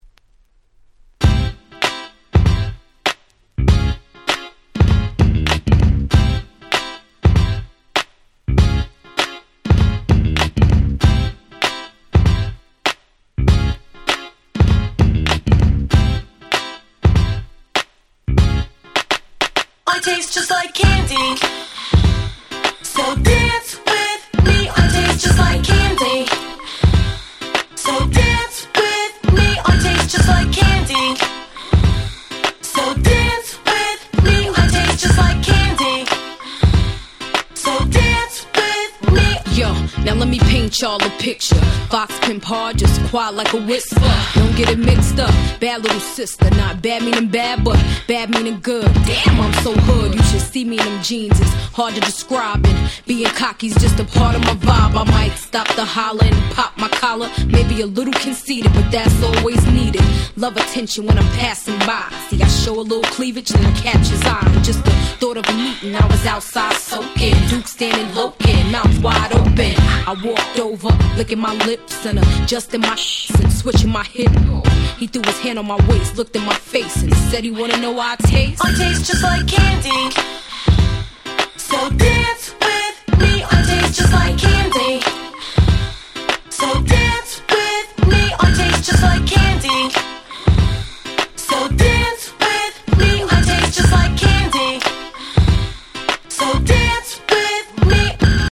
自分が当時好んで使用していた曲を試聴ファイルとして録音しておきました。